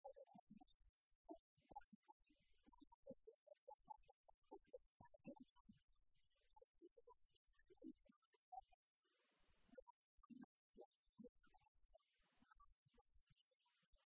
Milagros Tolón, portavoz de Empleo del Grupo Parlamentario Socialista
Cortes de audio de la rueda de prensa